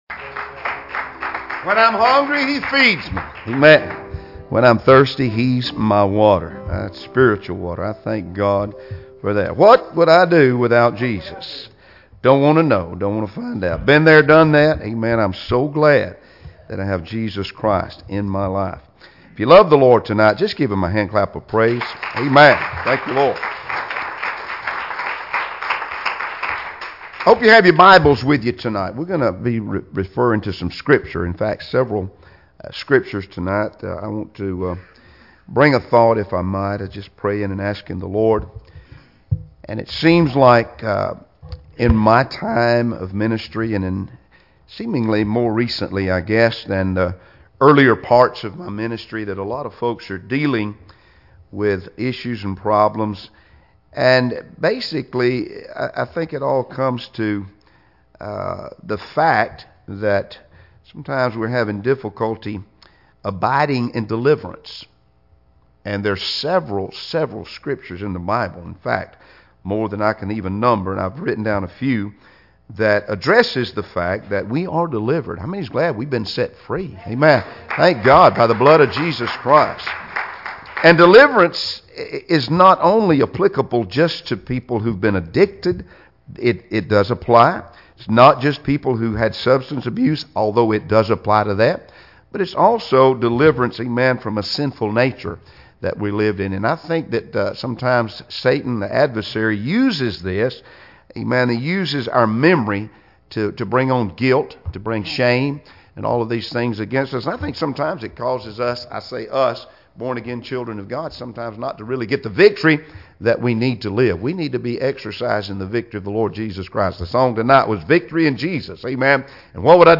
Service Type: Wednesday Evening Services